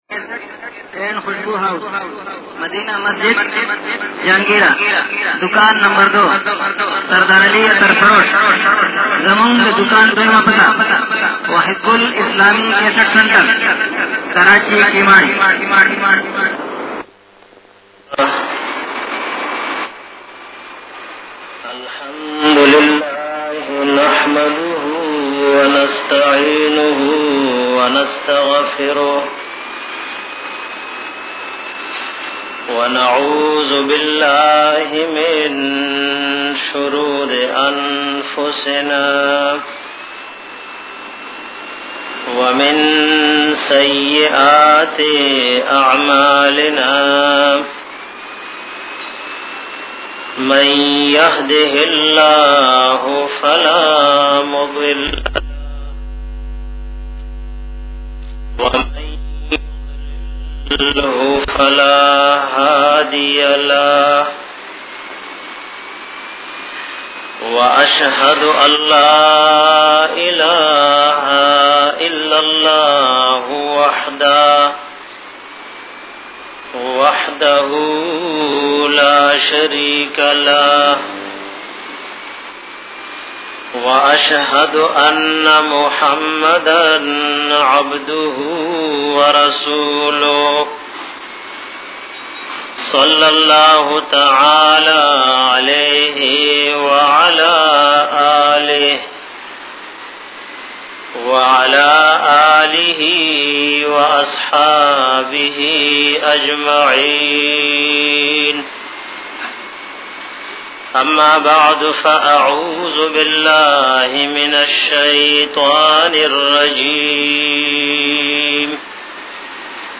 JUMMA BAYANAT